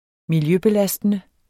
Udtale [ milˈjøbeˌlasdənə ]